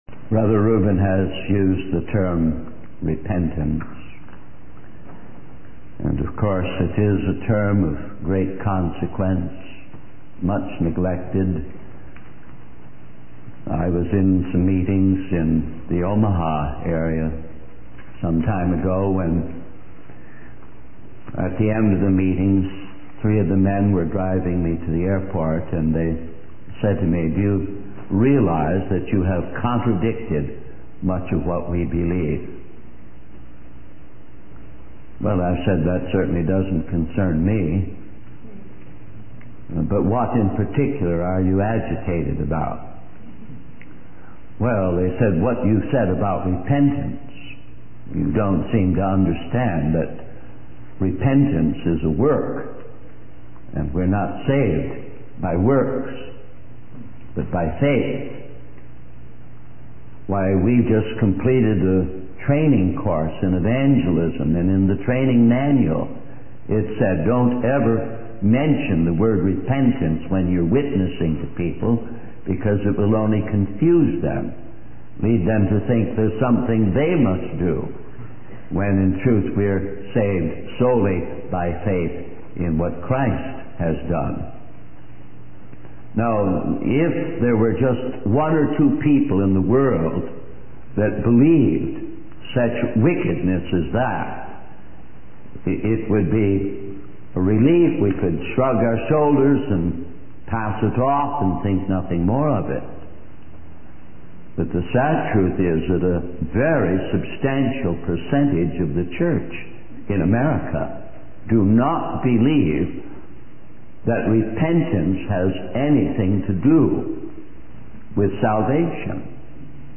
In this sermon, the speaker focuses on the subject of revival and emphasizes the importance of understanding its consequences.